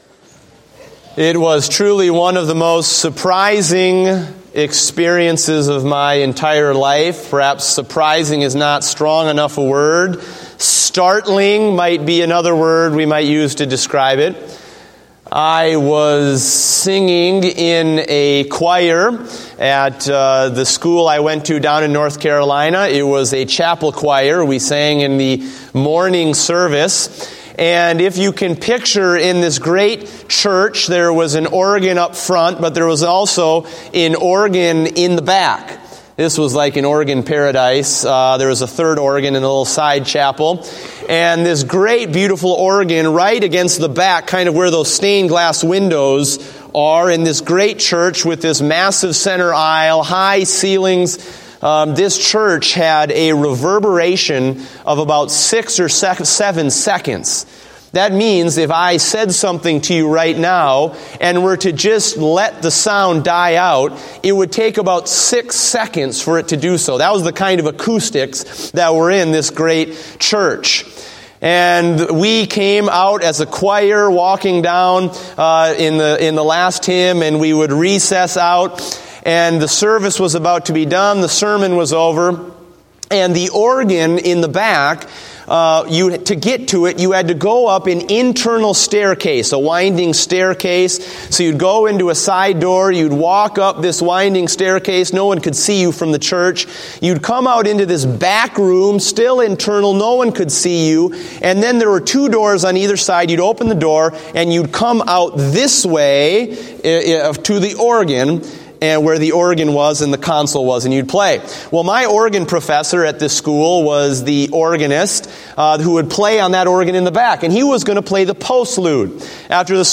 Date: January 3, 2016 (Morning Service)